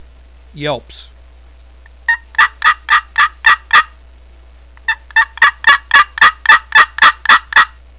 Yelp and cutt with plenty of volume by stroking the lip of the box against the rectangular piece of slate.
• Makes excellent raspy and smooth yelps, clucks, purrs, whines, and cutts at any volume
ccscratchyelps8.wav